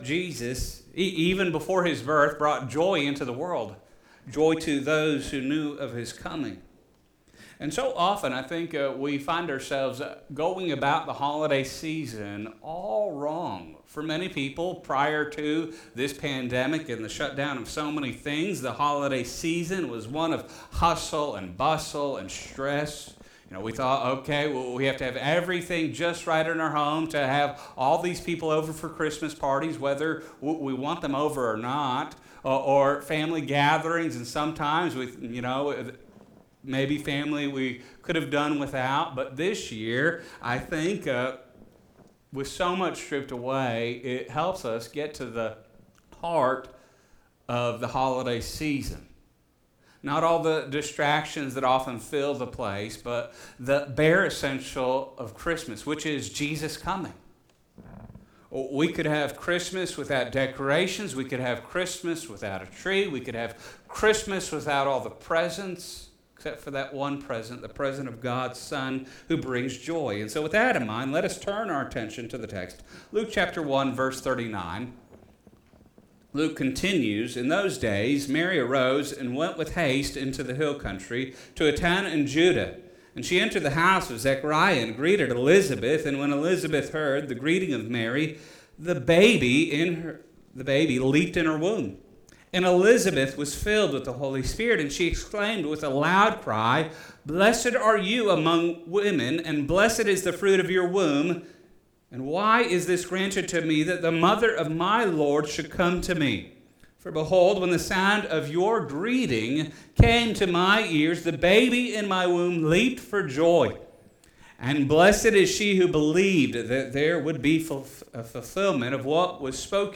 1:39-56 Service Type: Sunday Morning « The Spirit in the Life of Christ Hope in Life Ecclesiastes 91